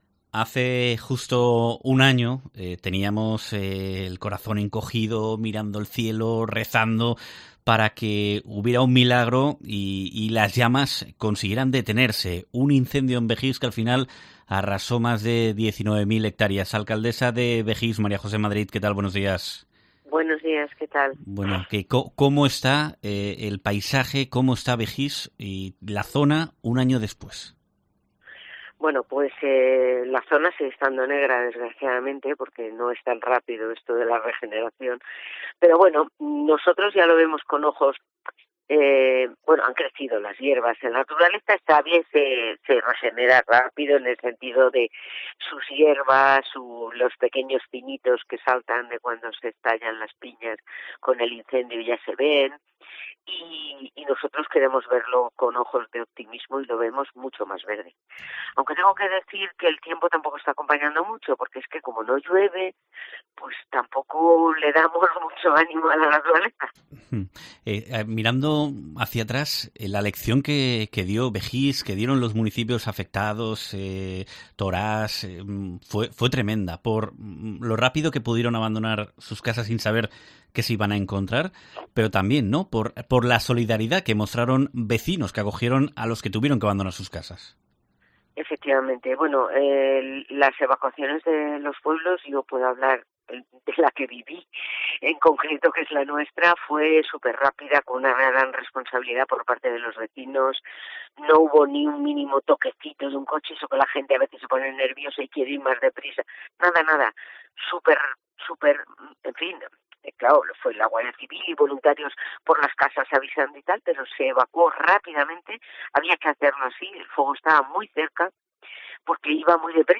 AUDIO: Bejís mantiene un alto número de visitantes un año después del terrible incendio forestal, como en COPE celebra la alcaldesa, María José Madrid